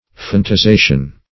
phonetization.mp3